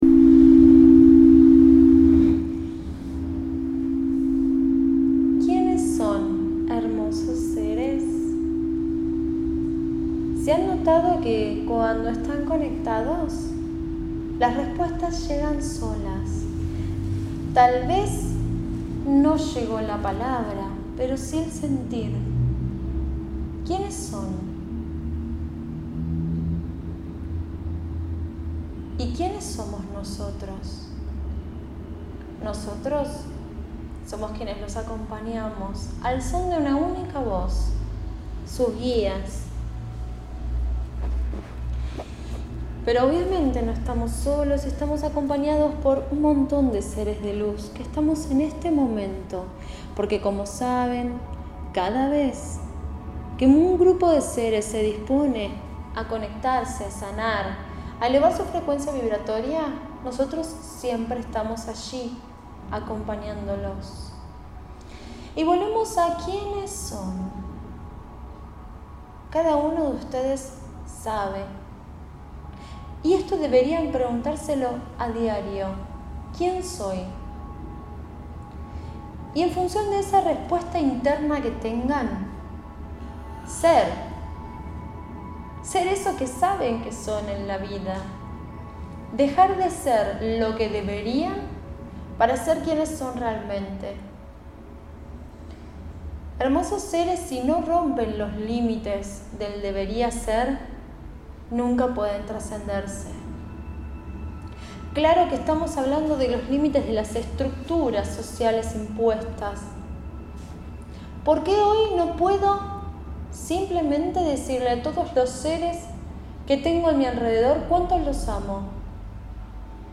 Este mensaje fue dado por los Guías y Maestros a los participantes del Taller de Sanación Intensiva & Cuencos de Cristal de Cuarzo del día Sábado 5 de Noviembre de 2016, luego de una meditación intensa de sanación.